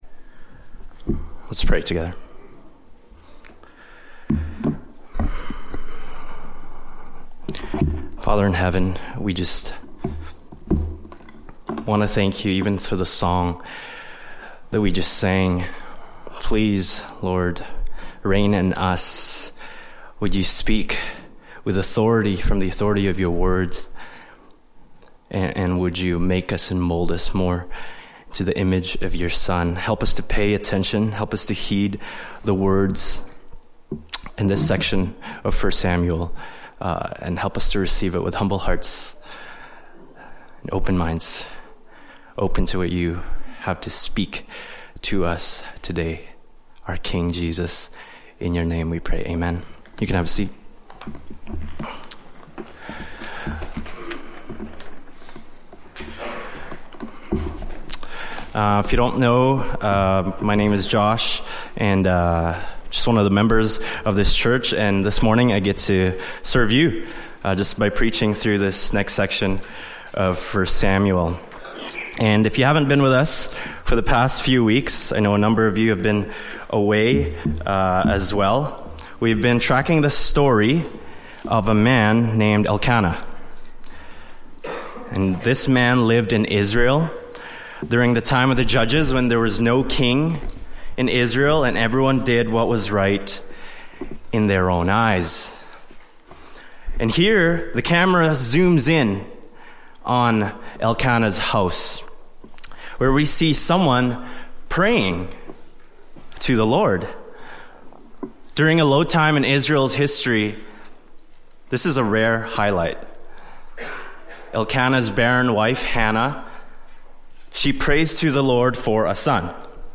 Service Type: Latest Sermon